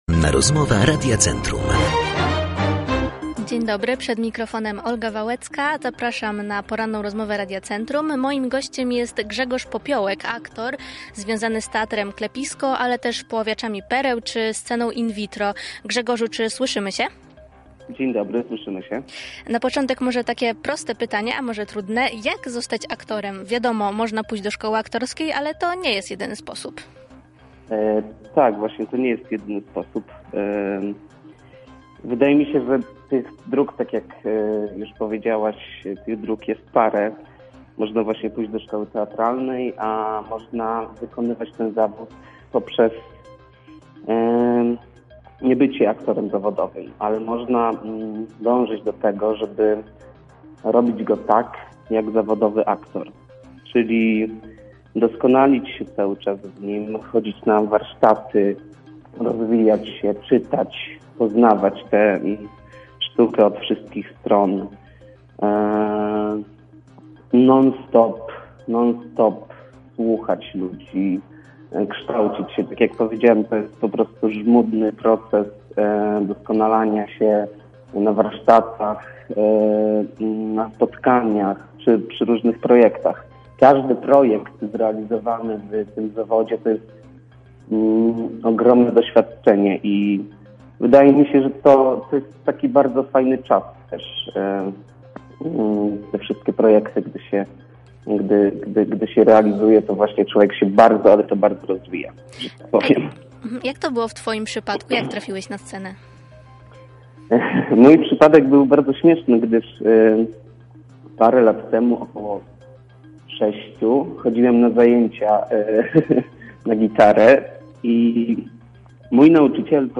Całej rozmowy możecie posłuchać poniżej: Poranna Rozmowa Radia Centrum